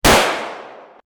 Gunshot 05
Gunshot_05.mp3